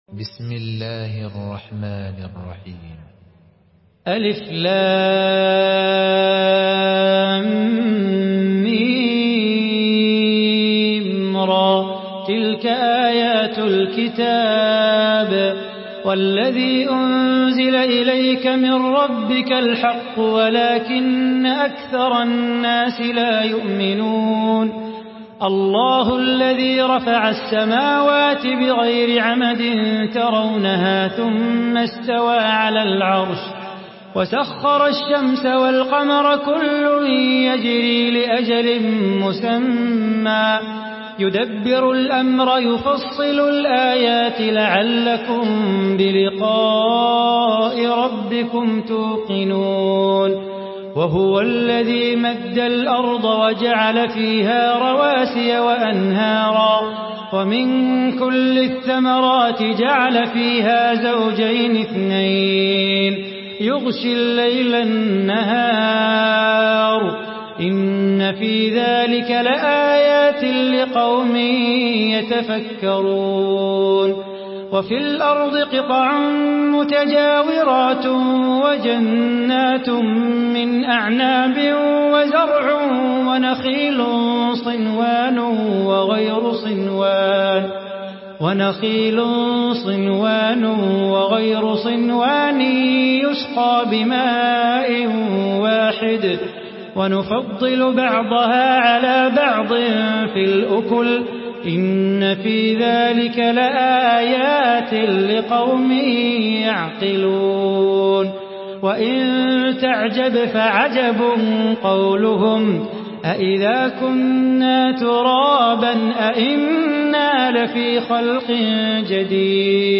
تحميل سورة الرعد بصوت صلاح بو خاطر
مرتل حفص عن عاصم